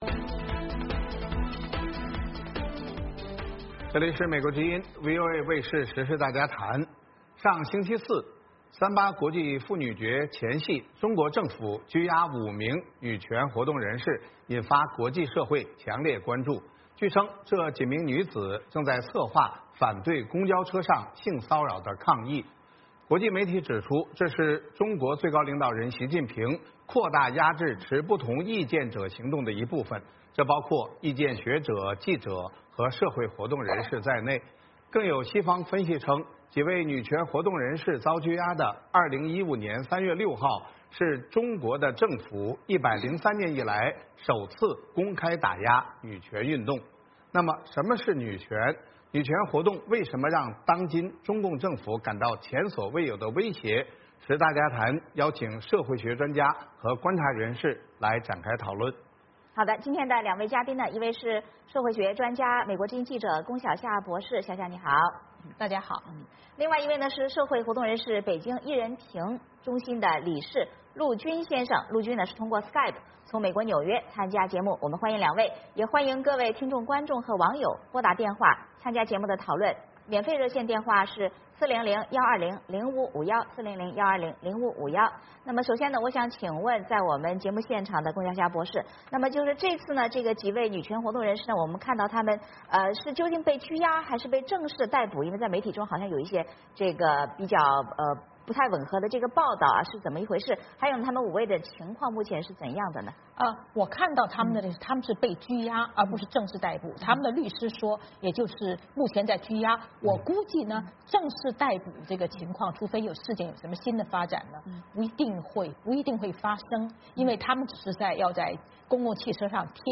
时事大家谈邀请社会学专家和观察人士来展开讨论。